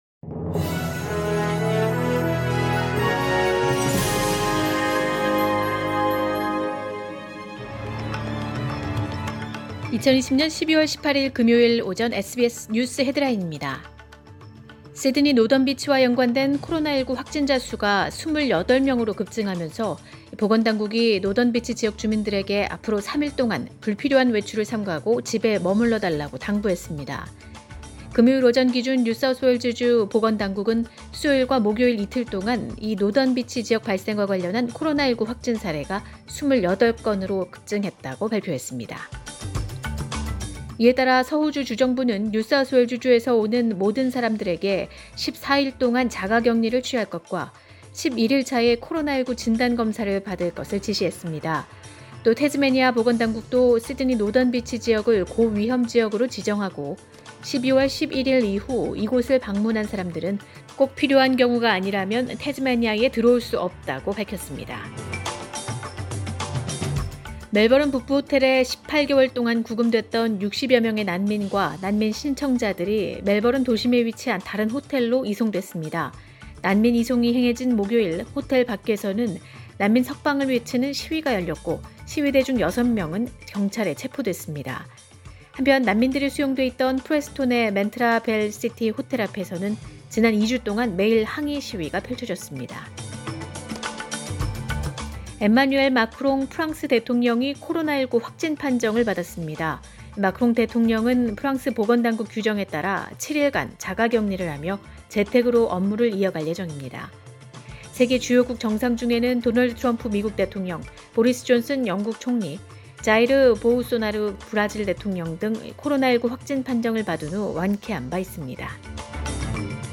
2020년 12월 18일 금요일 오전의 SBS 뉴스 헤드라인입니다.